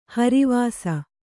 ♪ hari vāsa